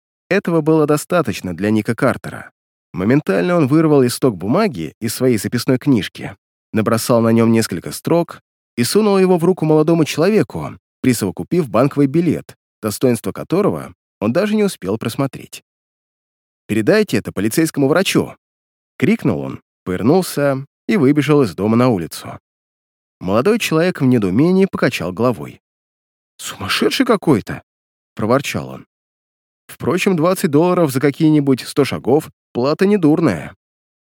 Тракт: Микрофон Rode K-2, предусилитель DBX 376